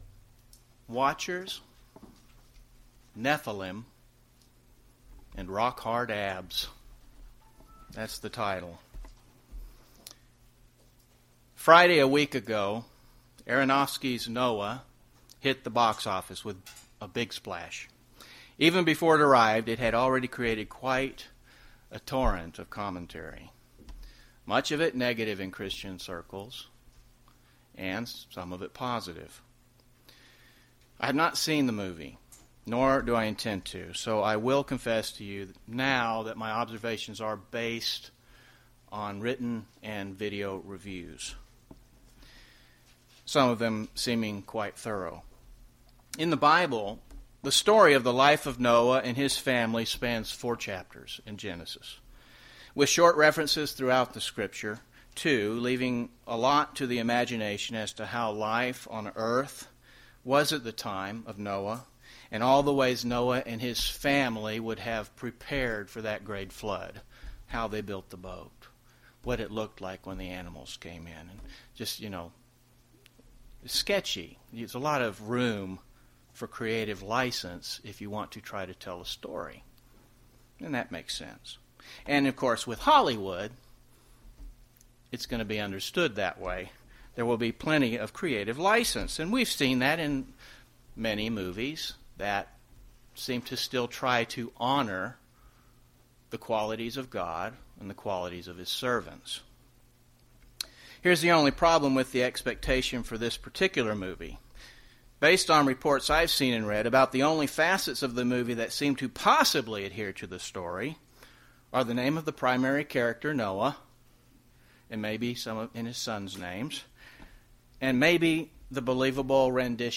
Aronofsky's Noah, debuted last Friday, March 28th, and has made a big splash at the box office, receiving a torrent of reviews, both positive and negative. This film embellishes on the true story of Noah on many levels, but this sermonette will discuss one facet of the movie, "The Watchers", and compare it to the Bible.